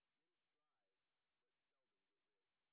sp06_white_snr10.wav